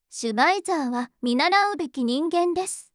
voicevox-voice-corpus / ita-corpus /もち子さん_怒り /EMOTION100_002.wav